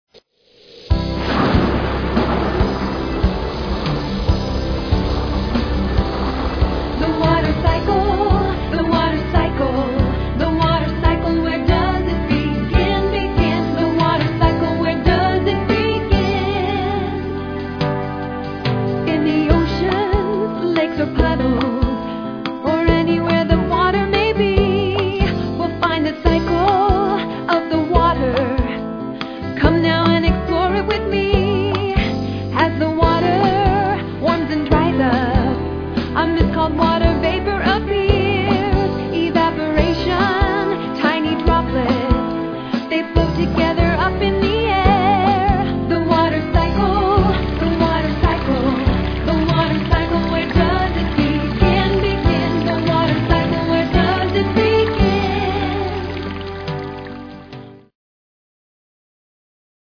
A Science Song for Young Children
song clip